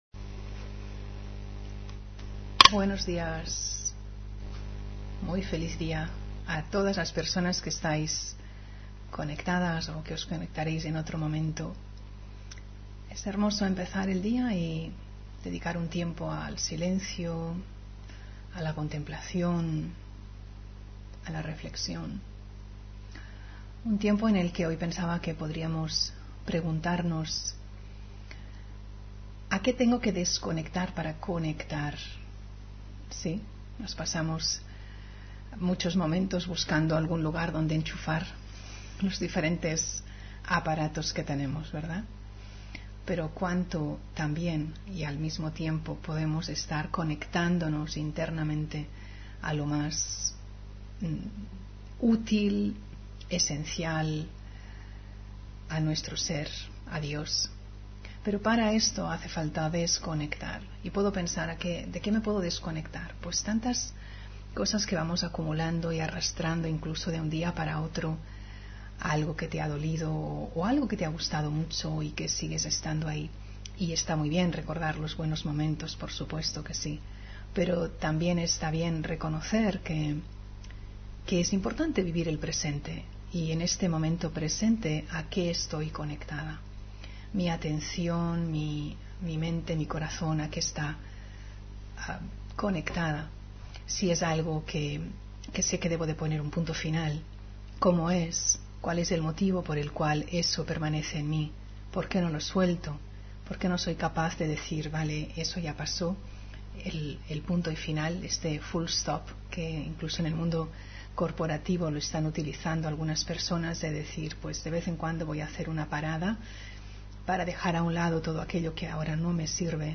Meditación de la mañana: Liviano y luminoso